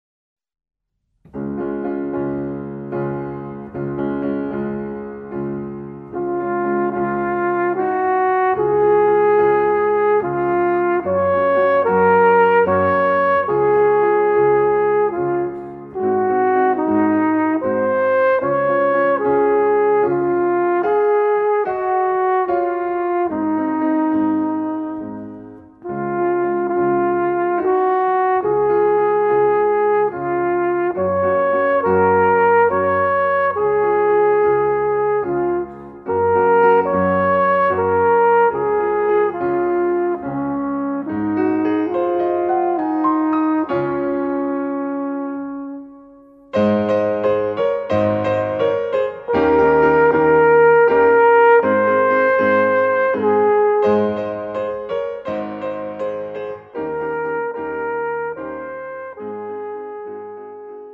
Instrumentalnoten für Trompete